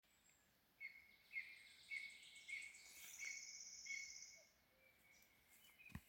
Birds -> Birds of prey ->
Lesser Spotted Eagle, Clanga pomarina
Notes/balss no meža, tad izlido ārā.